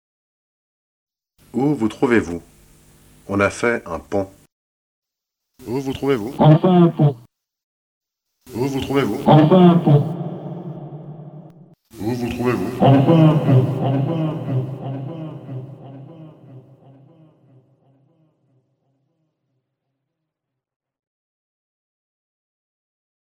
J’utilise des ondes Allemandes ou slaves (non latines) stables, non brouillées.
Exemples de Transcommunication instrumentale (T.C.I.)